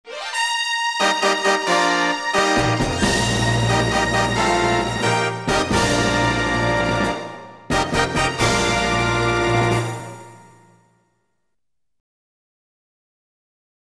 Фанфары праздничные (еще можно послушать здесь)